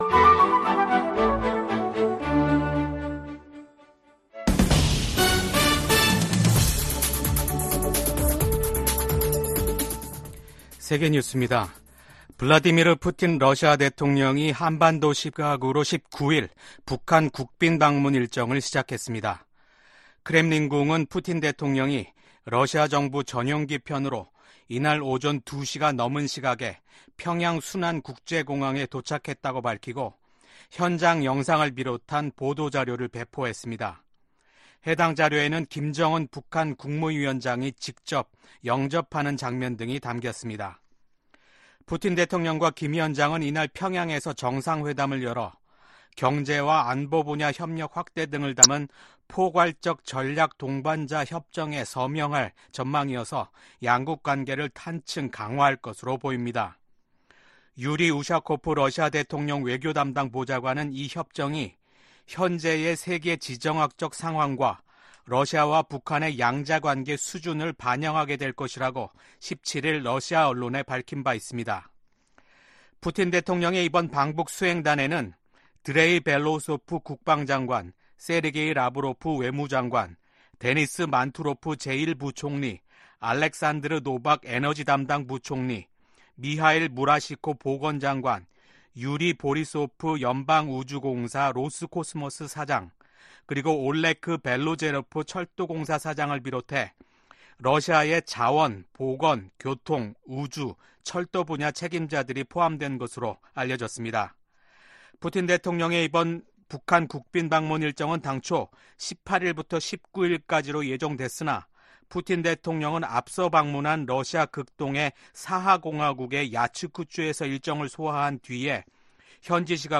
VOA 한국어 아침 뉴스 프로그램 '워싱턴 뉴스 광장' 2024년 6월 19일 방송입니다. 미 국무부는 블라디미르 푸틴 러시아 대통령의 방북과 두 나라의 밀착이 미국뿐 아니라 국제사회가 우려하는 사안이라고 지적했습니다. 미국의 전문가들은 러시아가 푸틴 대통령의 방북을 통해 탄약 등 추가 무기 지원을 모색하고 북한은 식량과 경제 지원, 첨단 군사기술을 얻으려 할 것이라고 분석했습니다.